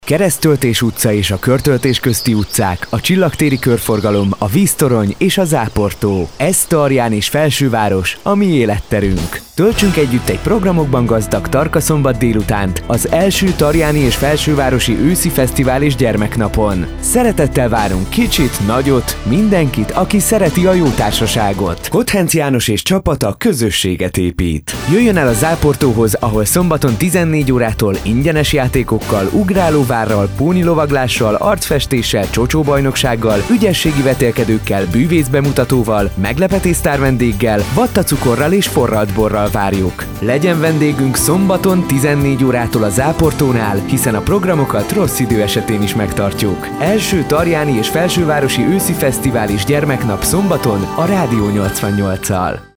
I. Tarjáni Őszi Fesztivál és Gyermeknap spot
7035oszifesztivalkesz2.mp3